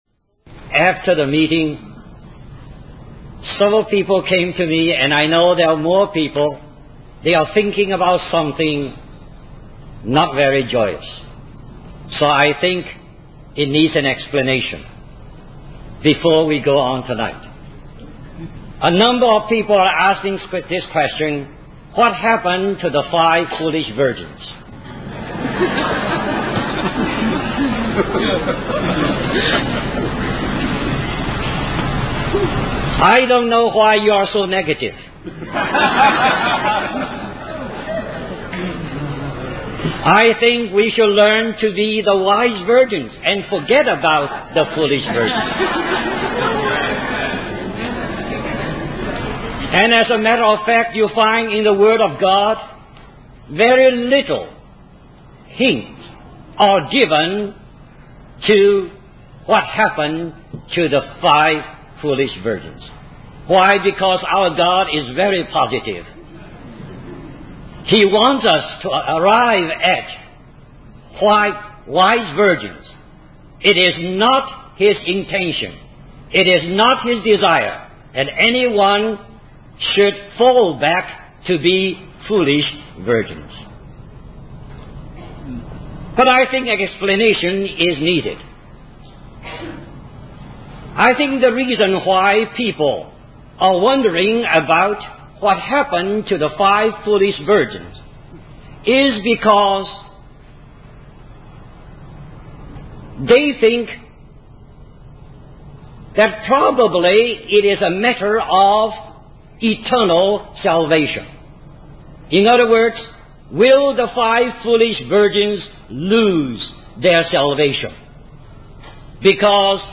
The preacher emphasizes the importance of worshiping God and not missing the opportunity to do so. The sermon also touches on the concept of the judgment seat of Christ, which is a family judgment where rewards or sufferings are determined, and the consequences for the five foolish virgins and the wicked servant are discussed.